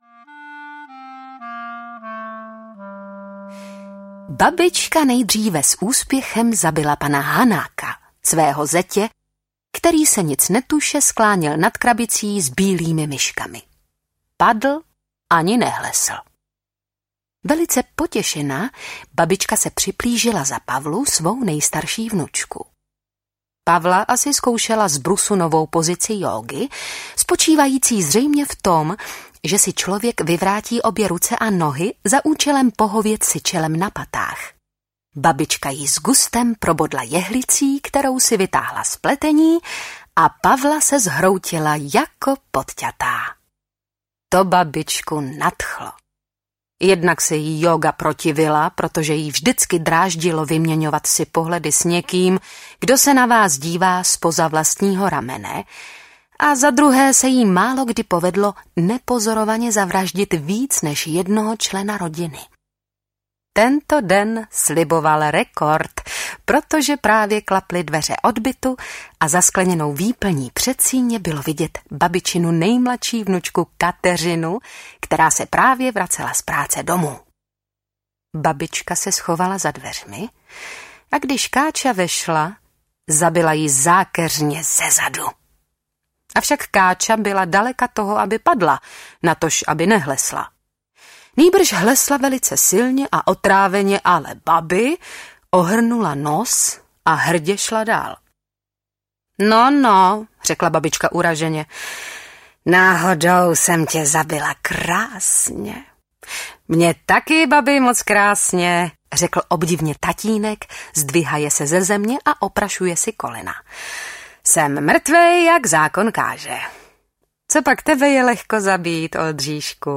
Taková normální rodinka audiokniha
Ukázka z knihy